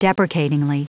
Prononciation du mot deprecatingly en anglais (fichier audio)
Prononciation du mot : deprecatingly
deprecatingly.wav